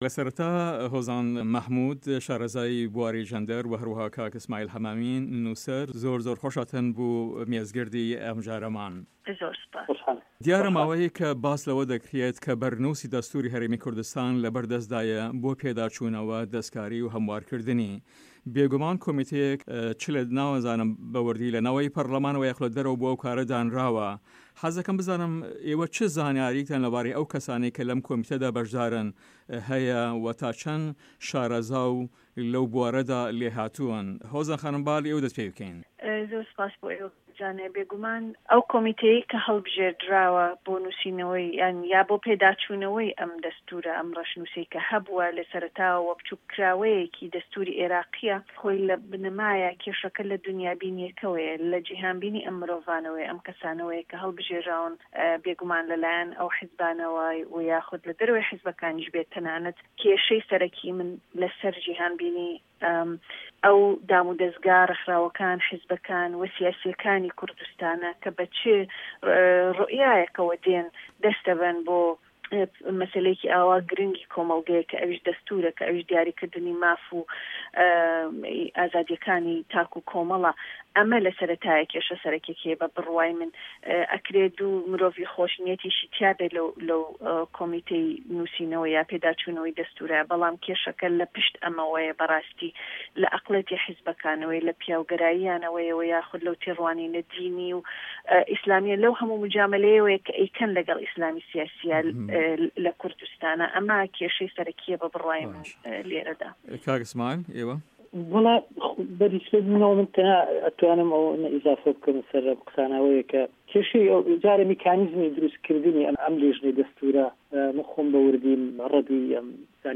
مێزگرد: بنه‌ماکانی ئیسلام و ده‌ستوری هه‌رێمی کوردستان